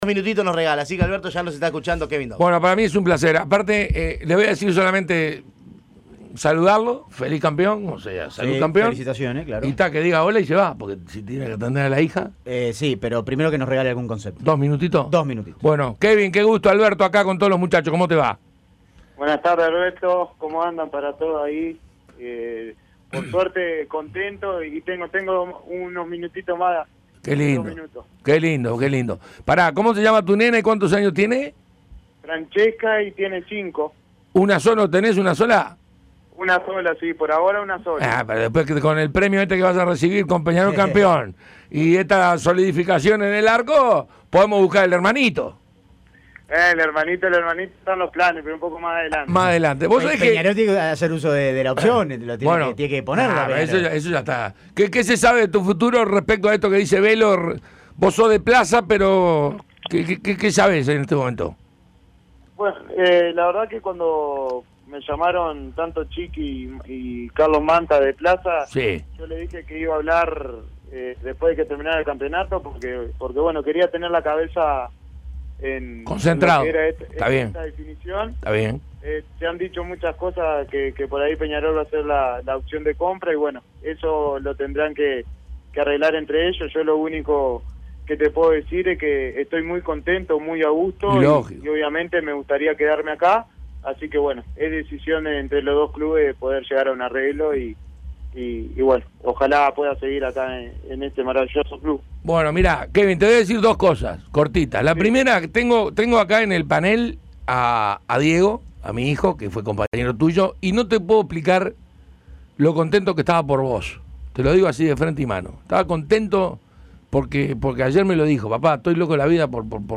Quien fuera también campeón con Plaza Colonia, habló con el panel de Tuya y Mía en El Espectador. Entrevista completa.